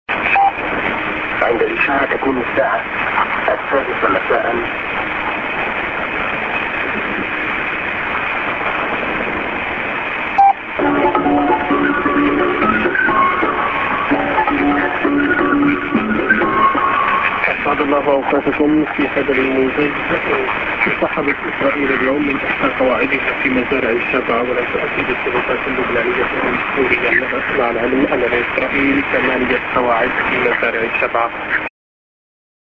Time check->ANN(men)->Time check->SJ->ANN(man)